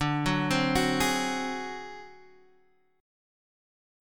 D7b5 chord